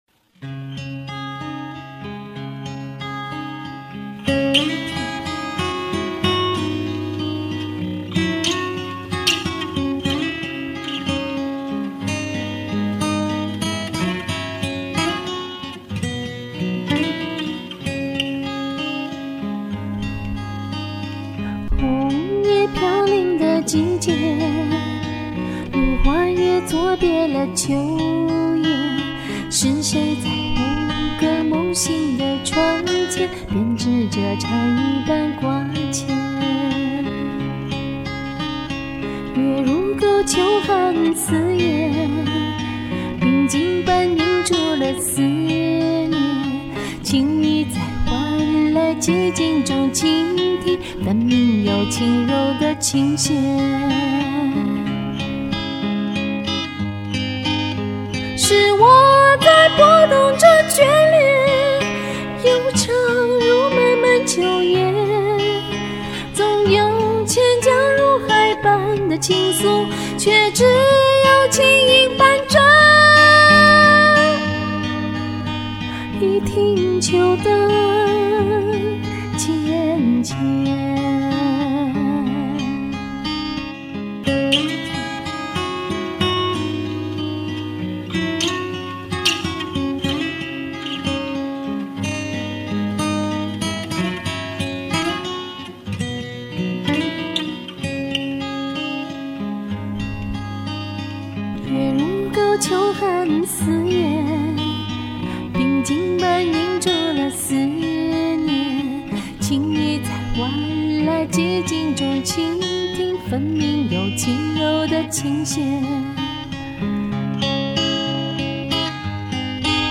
吉他伴奏